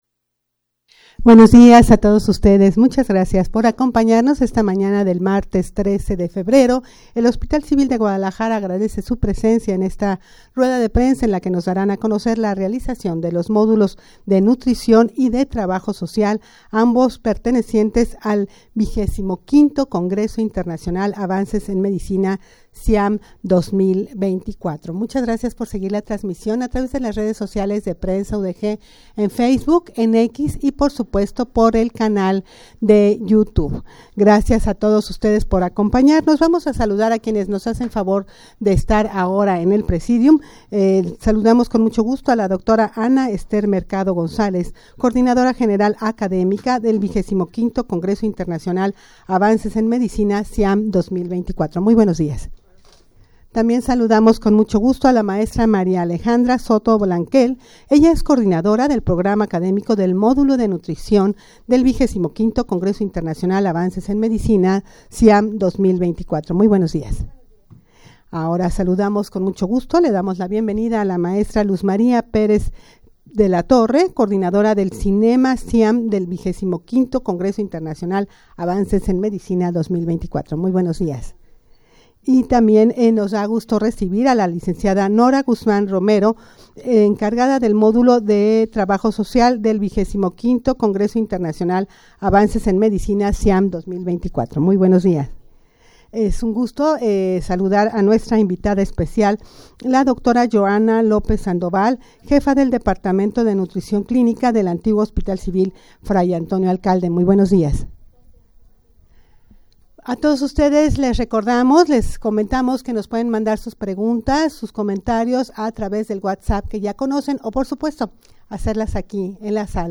Audio de la Rueda de Prensa
rueda-de-prensa-para-dar-a-conocer-la-realizacion-de-los-modulos-de-modulos-de-nutricion-y-trabajo-social.mp3